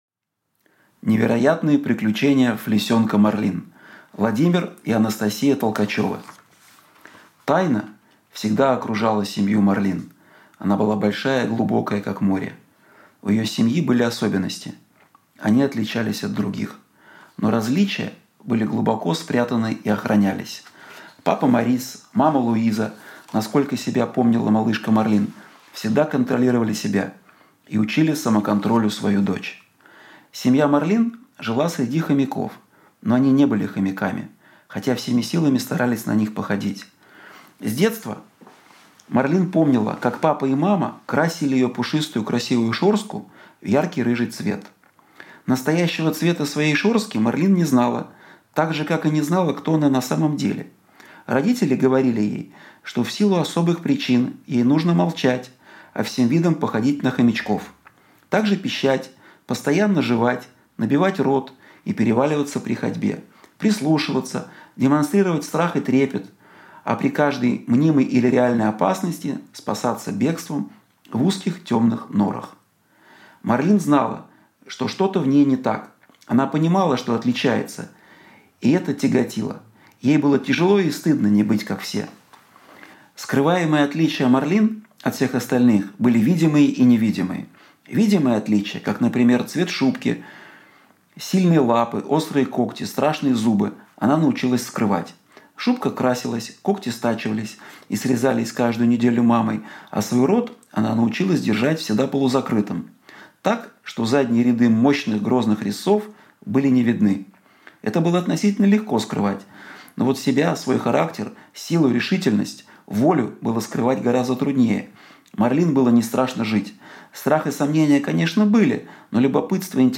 Аудиокнига Невероятные приключения флисёнка Марлин | Библиотека аудиокниг